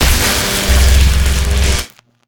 electrified.LN65.pc.snd.wav